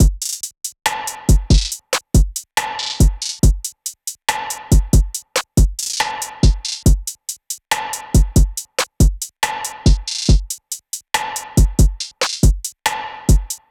SOUTHSIDE_beat_loop_toast_full_140.wav